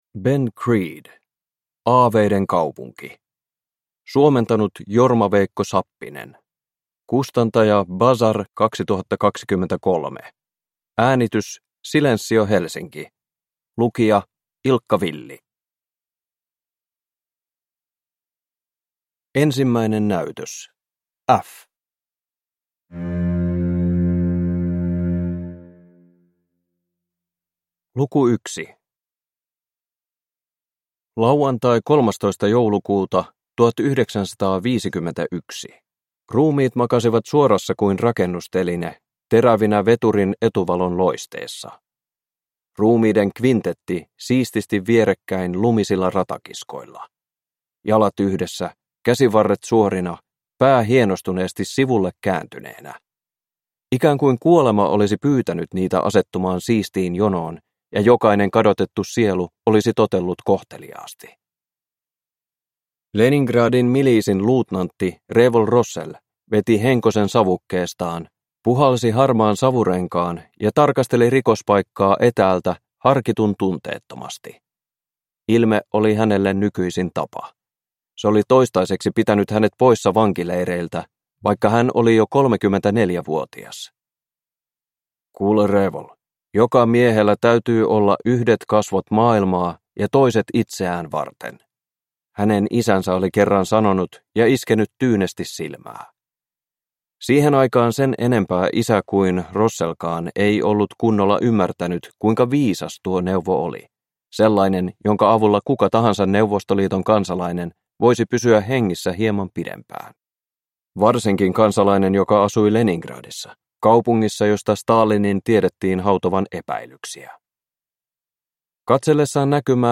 Aaveiden kaupunki – Ljudbok – Laddas ner